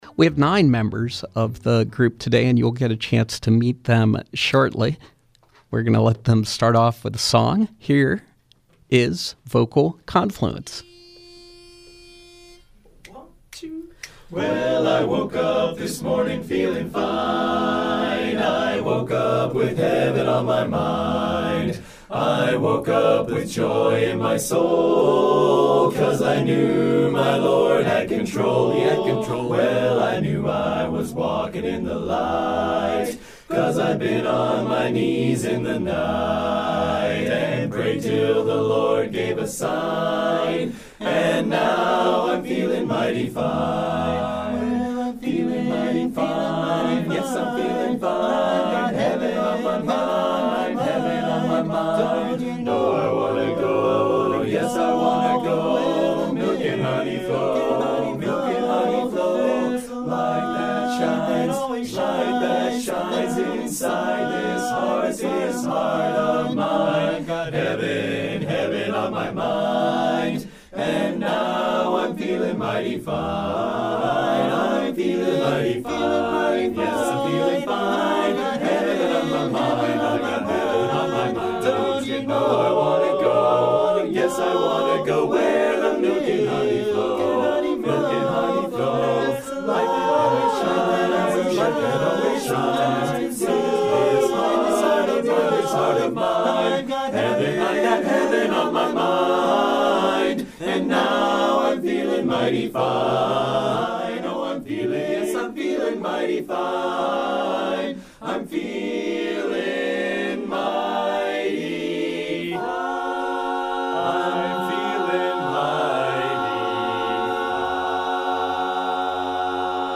men’s a cappella chorus